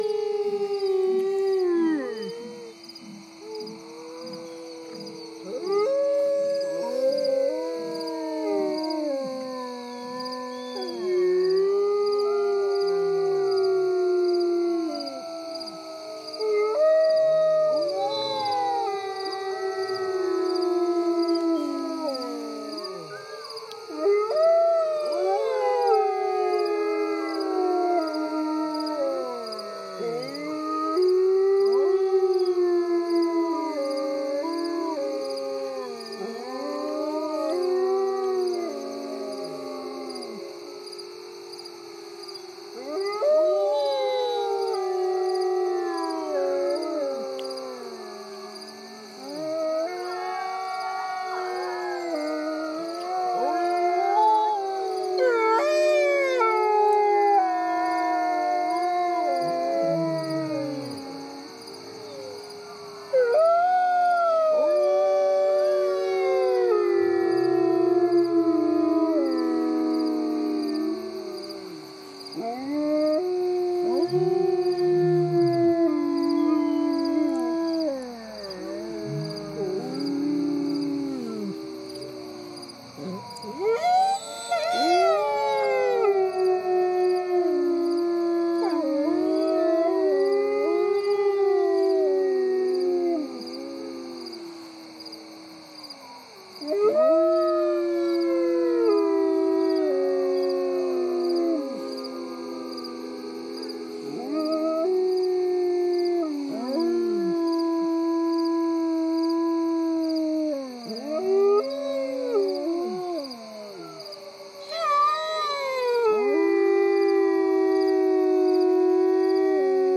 Enjoy over 4 minutes of "Live" emotional audio, of two epic Grey Wolves named "Wotan & Cheyenne" howling!!